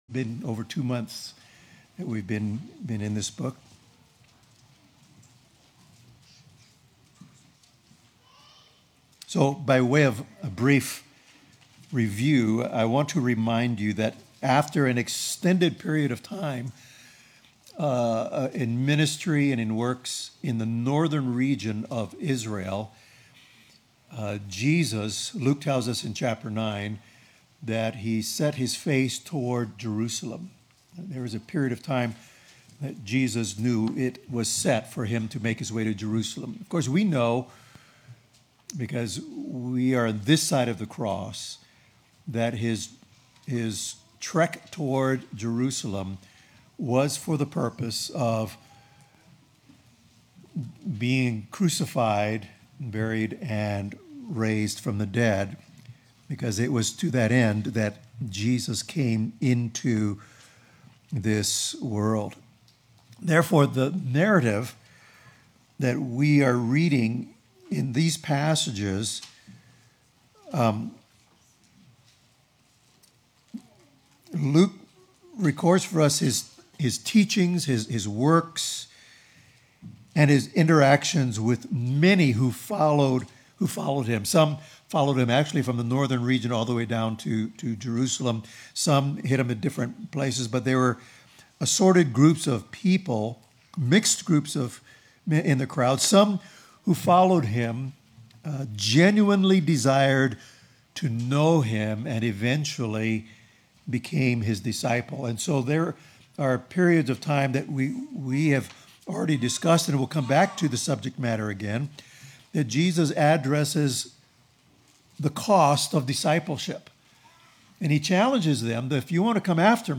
Luke 13:22-30 Service Type: Morning Service Strive to enter through the narrow door.